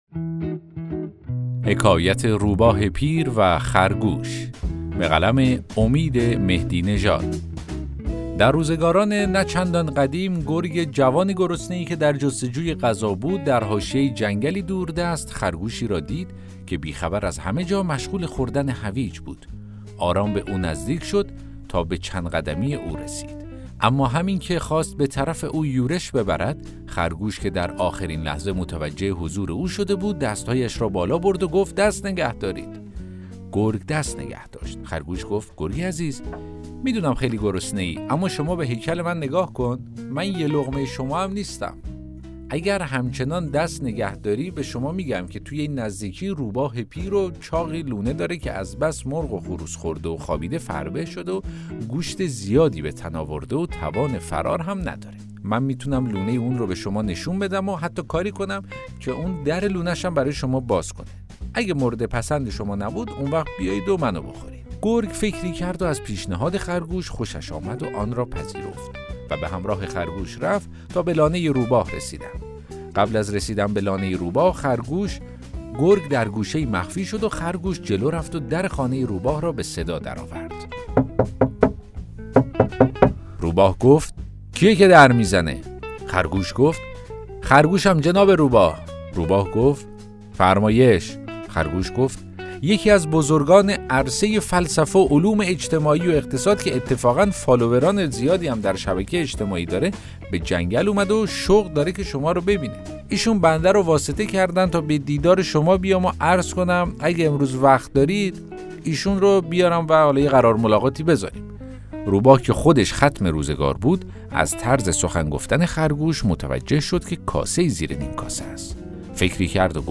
داستان صوتی: حکایت روباهِ پیر و خرگوش